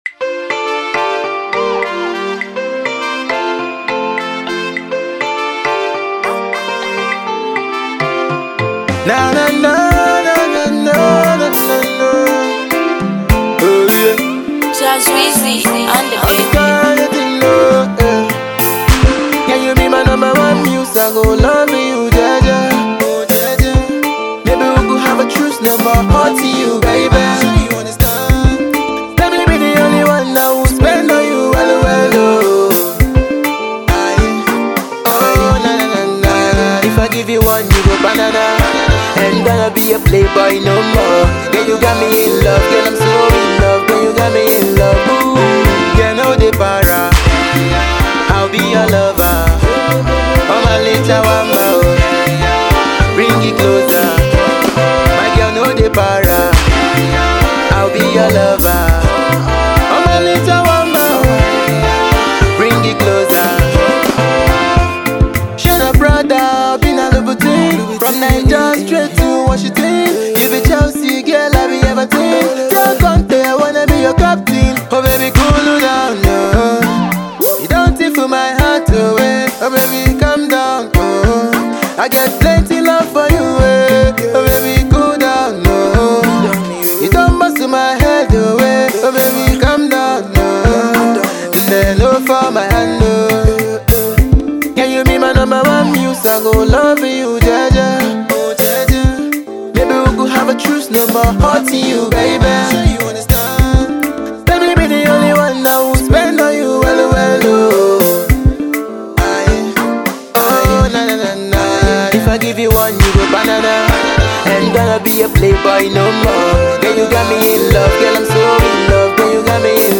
with a mind blowing afrobeat love sensational tune